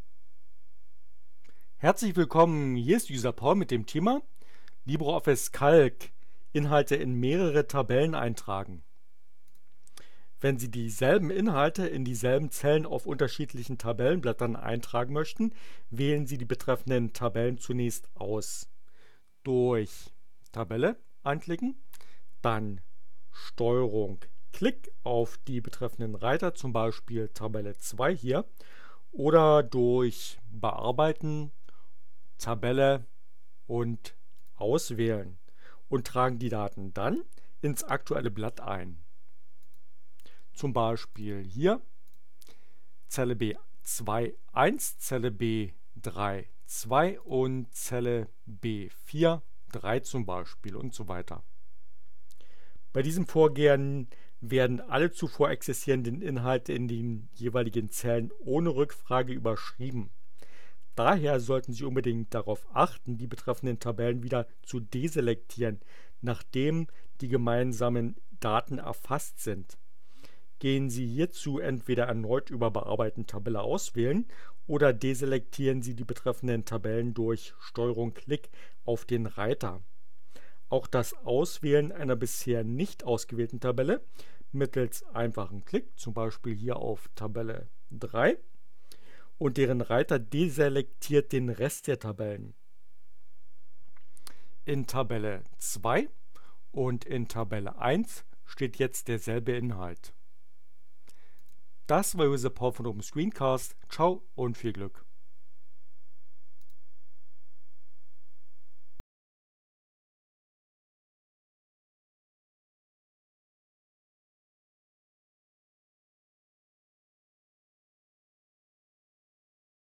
Tags: CC by, Linux, Neueinsteiger, ohne Musik, screencast, LibreOffice, Calc